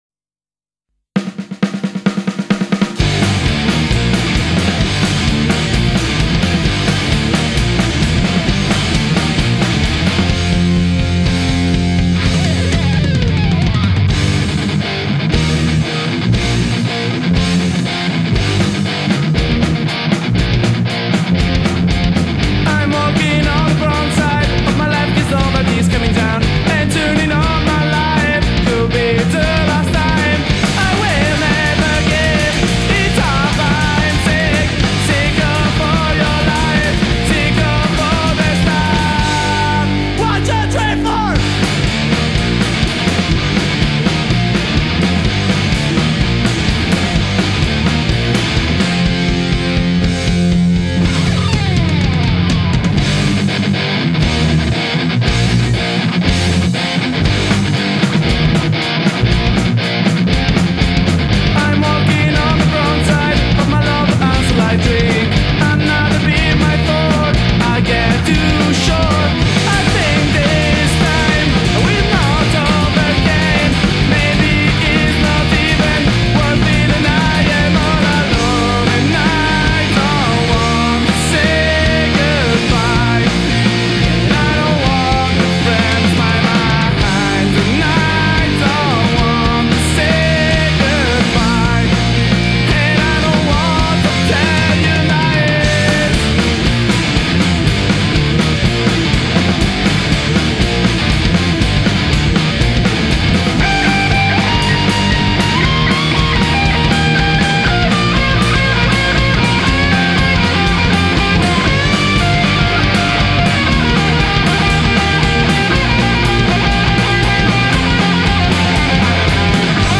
Genere: Pop Punk